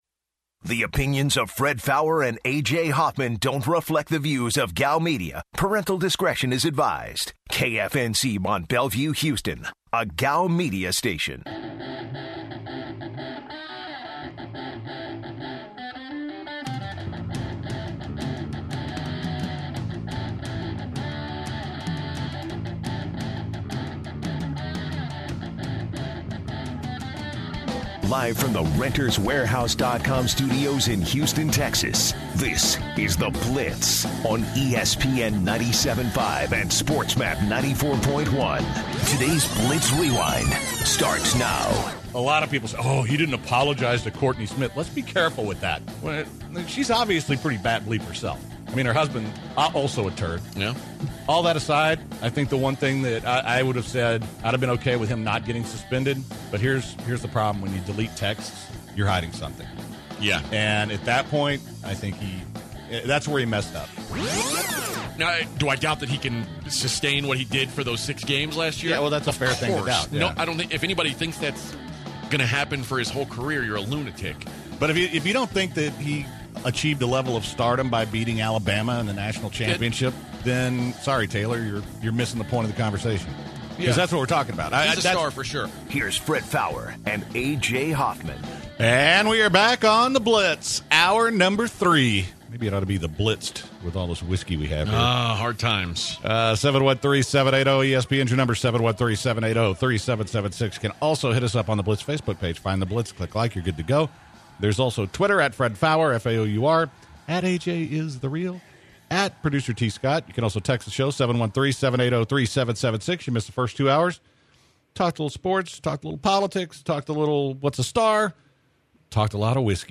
They talk the Tiger Woods vs. Phil Mickleson showdown with a caller and what they’d be willing to pay to watch. They talk the UT vs. Texas A&M rivalry and if it is still legit.